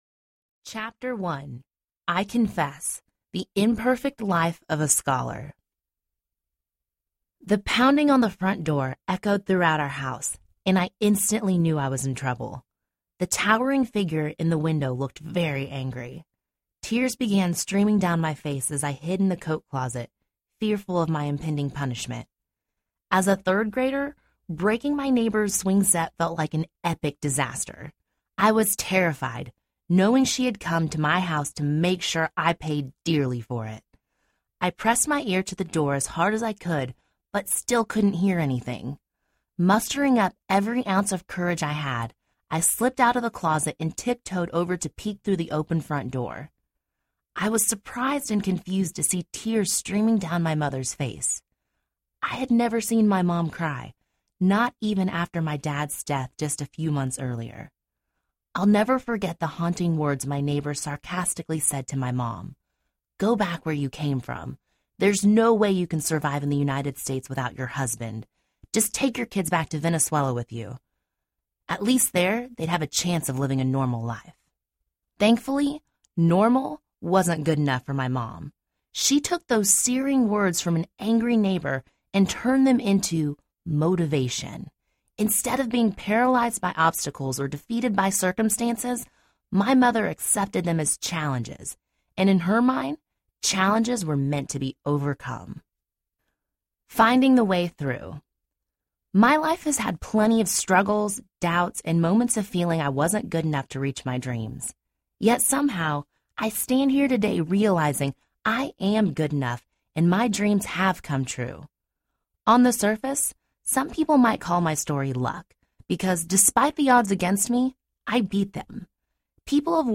Confessions of a Scholarship Winner Audiobook
5 Hrs. – Unabridged